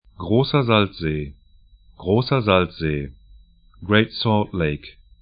Pronunciation
Grosser Salzsee [CH], Großer Salzsee [AT, DE] 'gro:sɐ 'zalts-ze: Great Salt Lake greɪt 'sɔ:lt 'leɪk en See / lake 41°10'N, 112°30'W